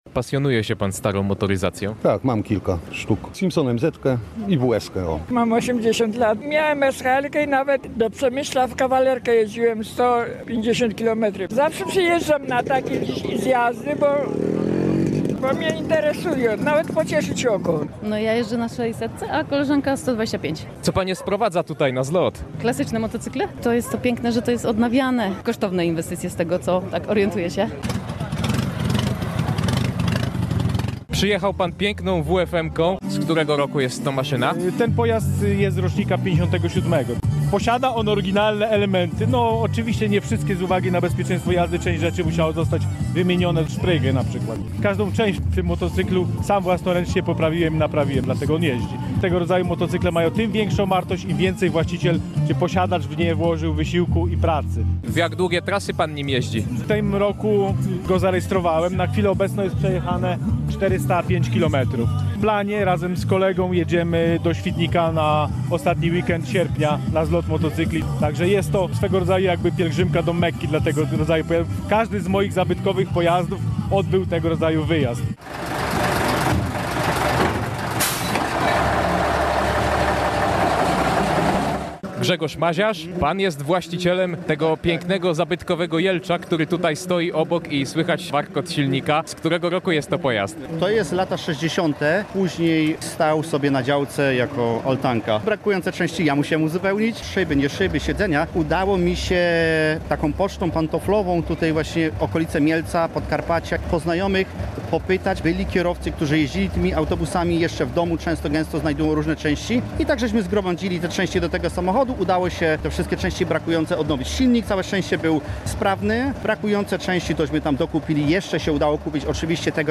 Na placu targowym w Grębowie stanęło w niedzielę (27.07) kilkaset pojazdów sprzed kilkudziesięciu lat.